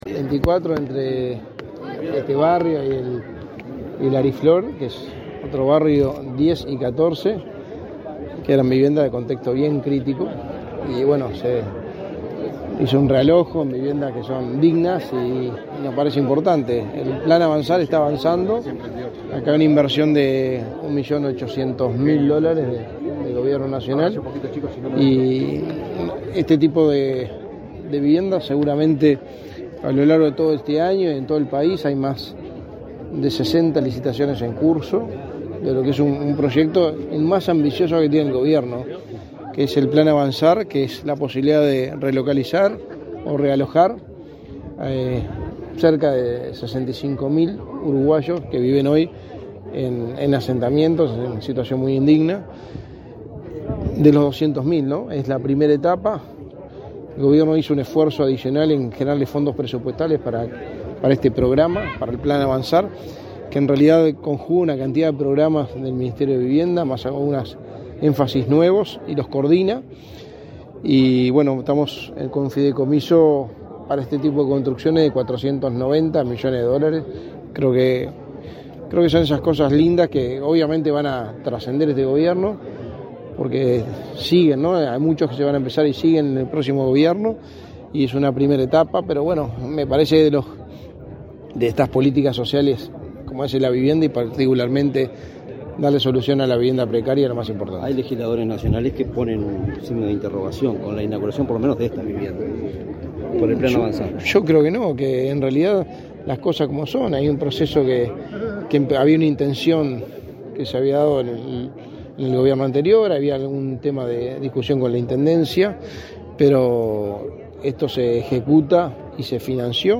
Declaraciones a la prensa del secretario de la Presidencia de la República, Álvaro Delgado
Tras participar en la entrega de viviendas de Mevir en Florida, este 2 de mayo, el secretario de la Presidencia, Álvaro Delgado, realizó declaraciones
delgado prensa.mp3